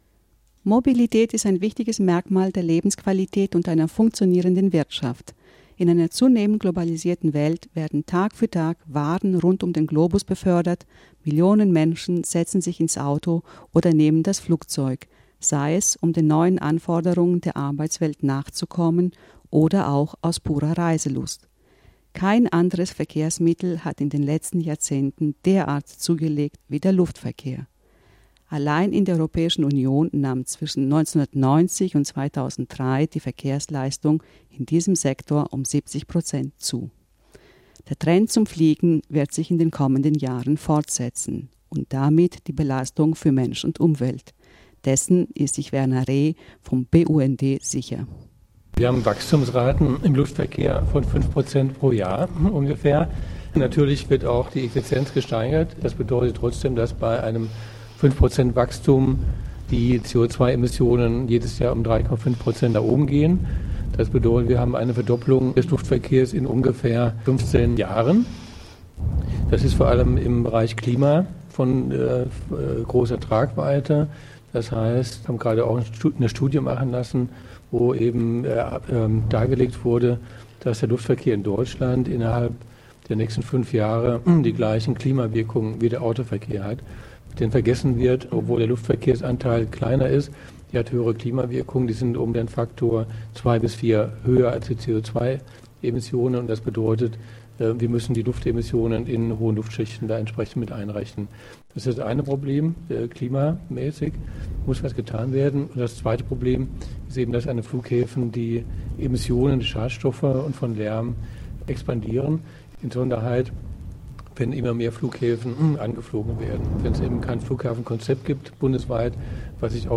Im Anschluss an die Veranstaltung bat das UBA drei Teilnehmer zu Wort: